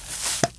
Berry and Bush sounds
BerryWithBush.wav